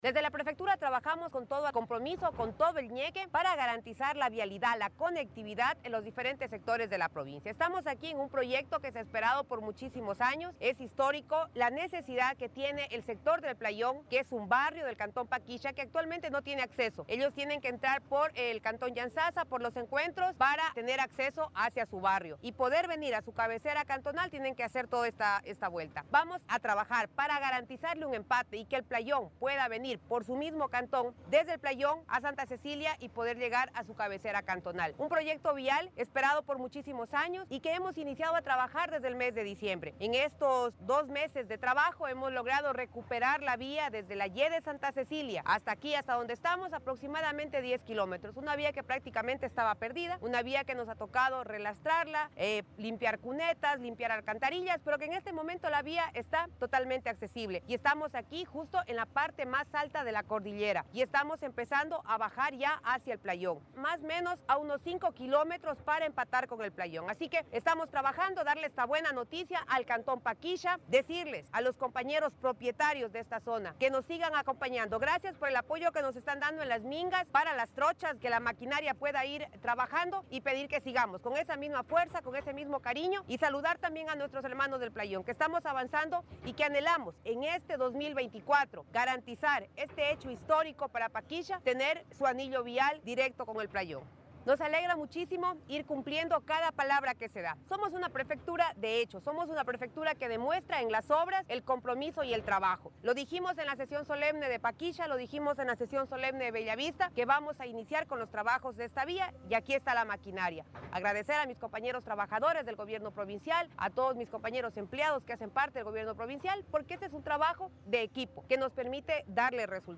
KARLA REÁTEGUI – PREFECTA